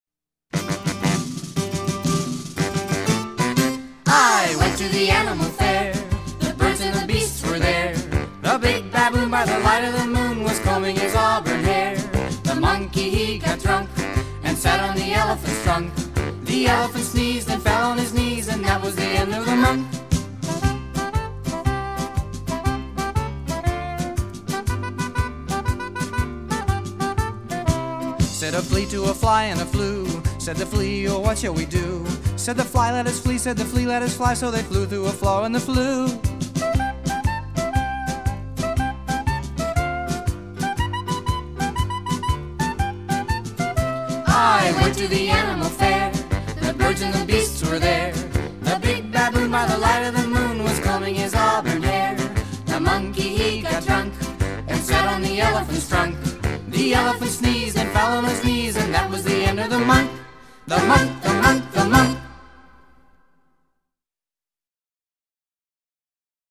英文儿童歌曲:Animal Fair 动物赶集 听力文件下载—在线英语听力室